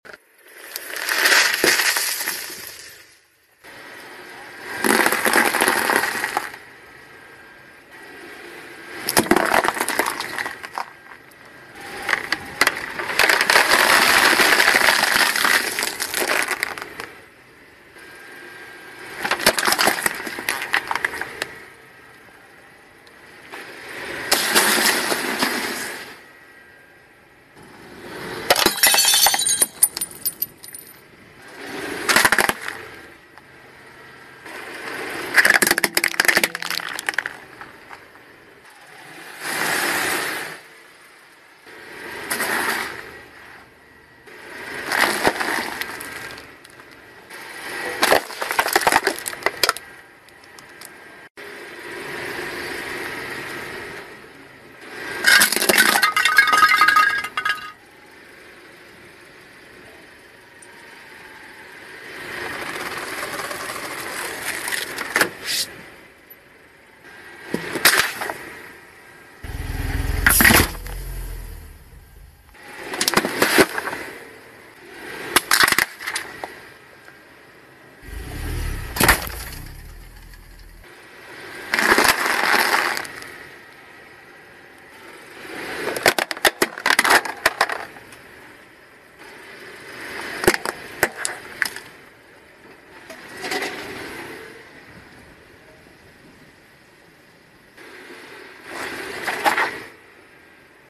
Super stress relieving sounds, do sound effects free download